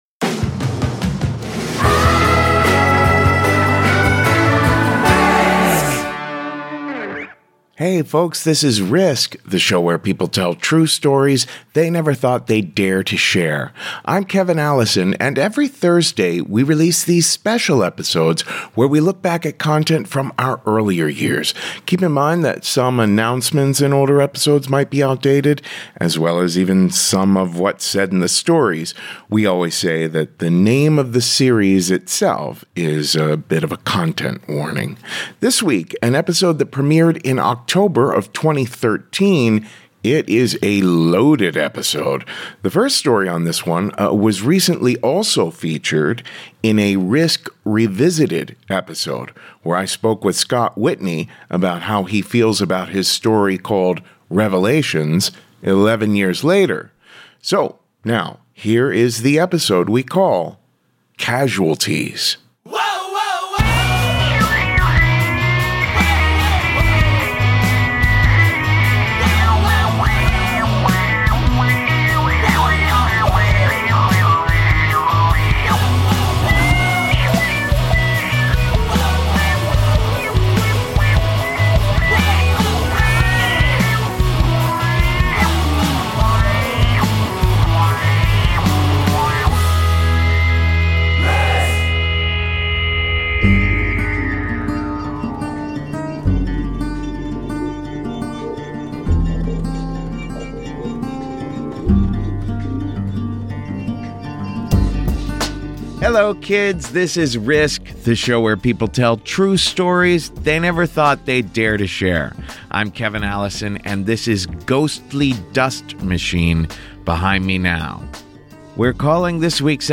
🎤Live Story: Boots on the Ground